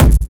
Snare set 2 013.wav